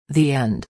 به همراه فایل صوتی تلفظ انگلیسی